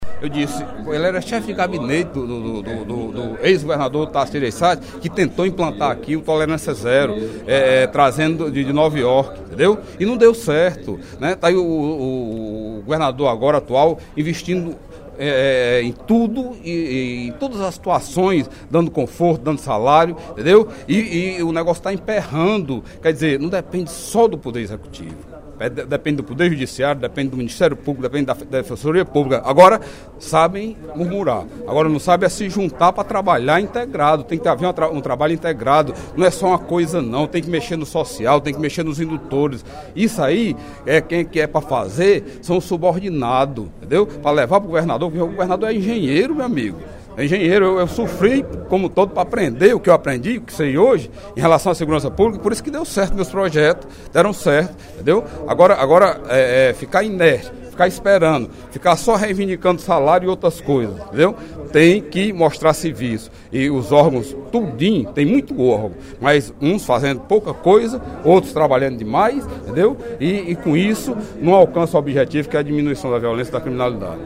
No primeiro expediente da sessão plenária desta quarta-feira (09/04), o deputado Delegado Cavalcante (PDT) defendeu o trabalho na área da segurança pública do governo Cid Gomes.
Em aparte, o deputado João Jaime (DEM) questionou os investimentos do Governo na segurança pública.